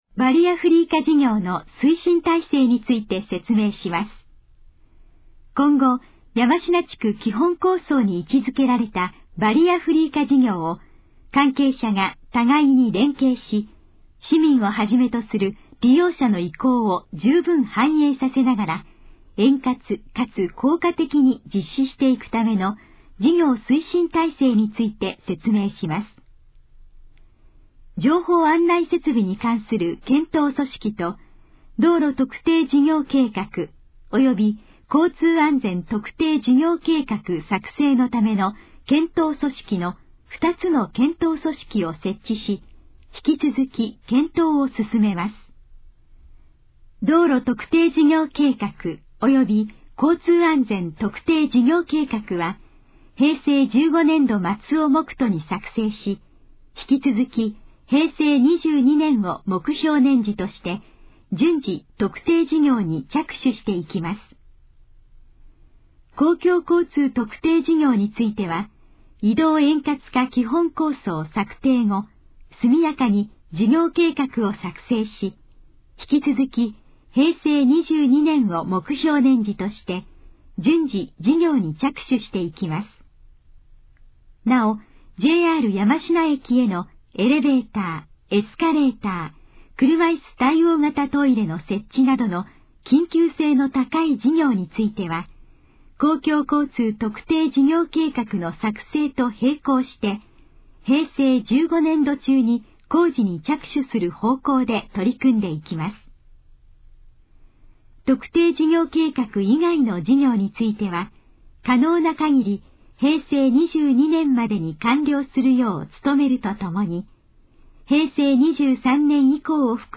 このページの要約を音声で読み上げます。
ナレーション再生 約331KB